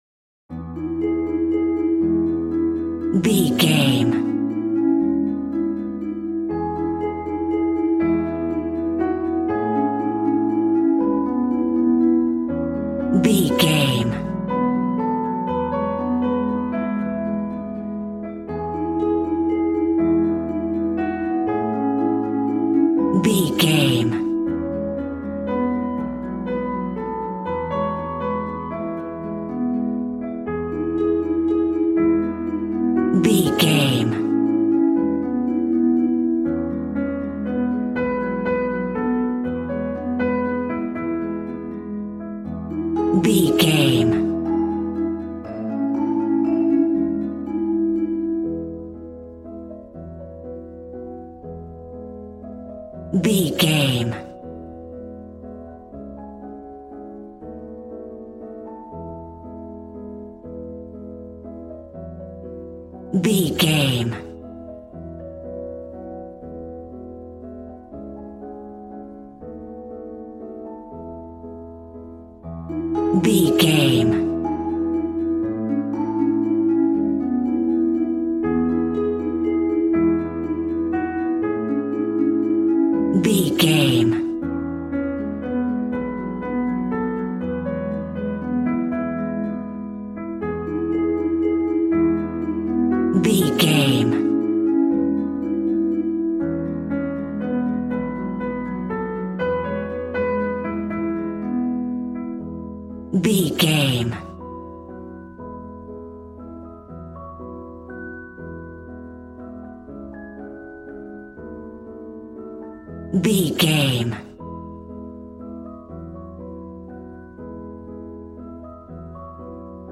Regal and romantic, a classy piece of classical music.
Ionian/Major
strings
violin
brass